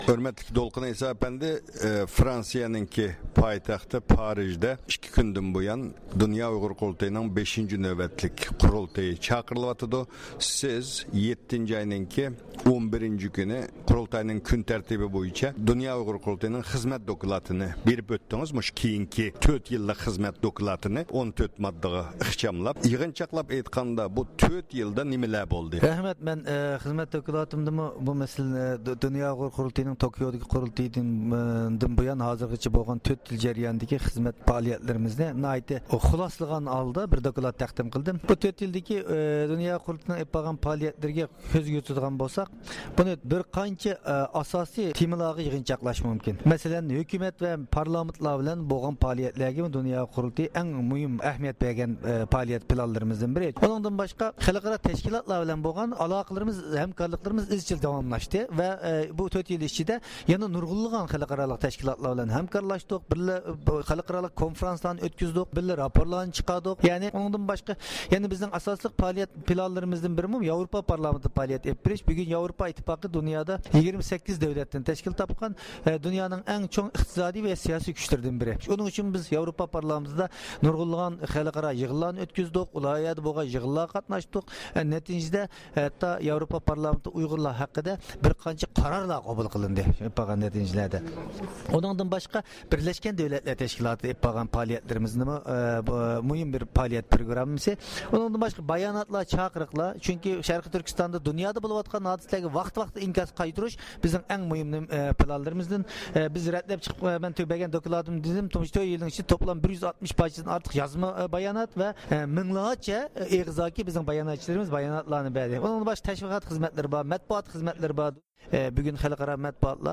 بىز خىزمەت دوكلاتى توغرىسىدا مەلۇمات ئىگىلەش ئۈچۈن دۇنيا ئۇيغۇر قۇرۇلتىيىنىڭ سابىق مۇدىرى دولقۇن ئەيسا، بەزى رەھبەرلەر ۋەكىللەر بىلەن سۆھبەت ئېلىپ باردۇق.